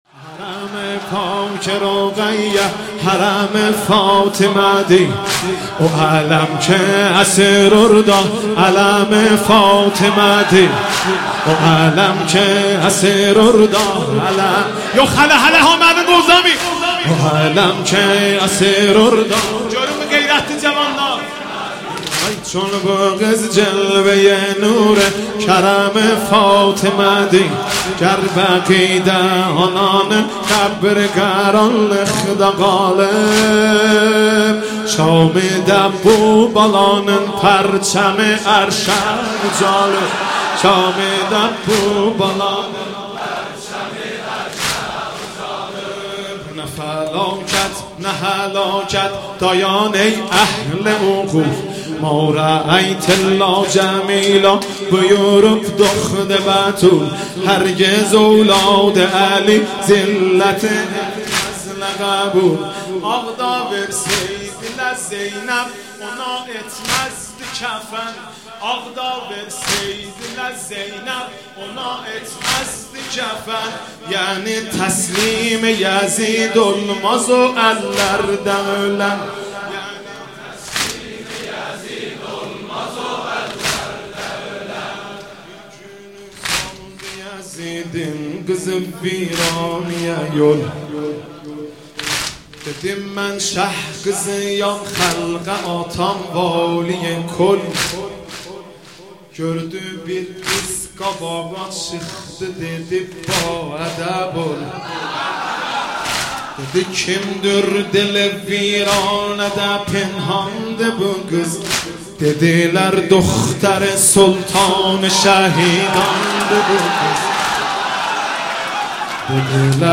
شب سوم محرم آذری